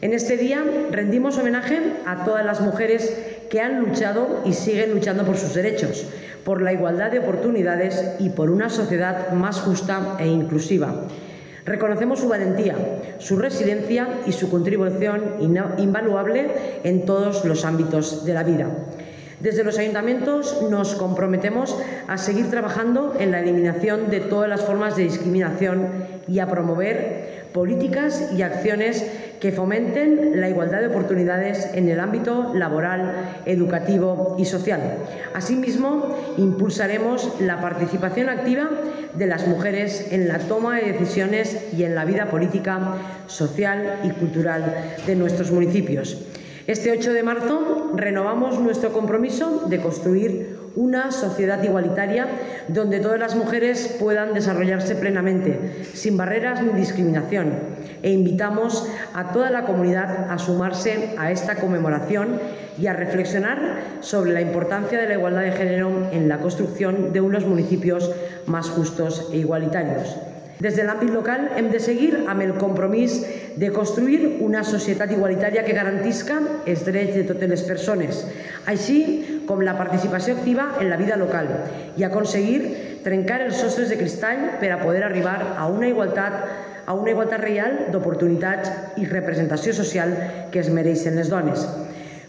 • La presidenta Marta Barrachina llig el manifest institucional, acte que obri la programació preparada amb motiu del 8-M, Dia Internacional de la Dona
Presidenta-Marta-Barrachina-lectura-manifiesto-8M.mp3